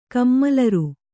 ♪ kammalaru